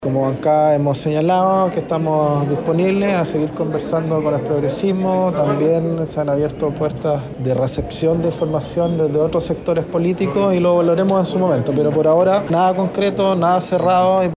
El jefe de bancada del PPD, Raúl Soto, acuñó el término del ‘Plan B’ y a estas alturas, admite que han ‘recibido información’ desde otros sectores.